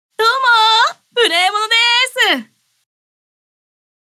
ダウンロード 中性_「どうも、無礼者です」
挨拶